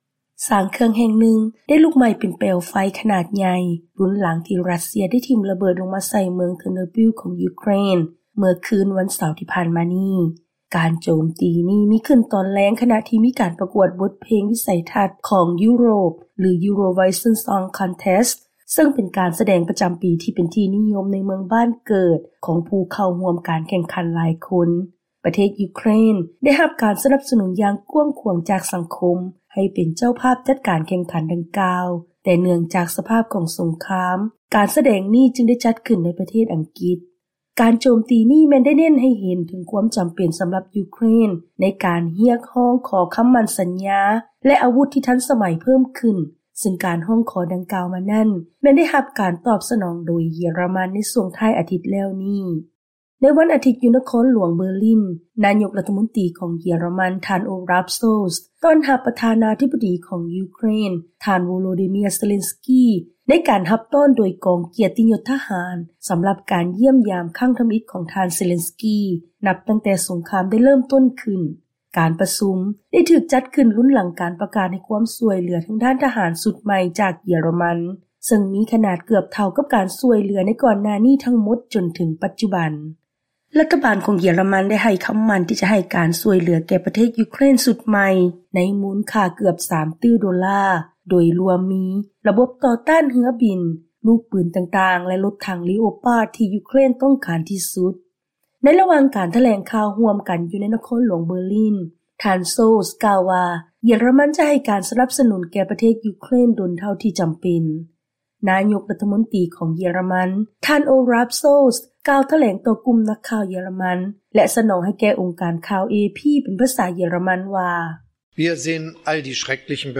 ເຊີນຟັງລາຍງານກ່ຽວກັບ ການເດີນທາງໄປຢ້ຽມຢາມເຢຍຣະມັນຂອງທ່ານເຊເລັນສກີ ລຸນຫຼັງປະເທດດັ່ງກ່າວປະກາດໃຫ້ການຊ່ວຍເຫຼືອຊຸດໃໝ່.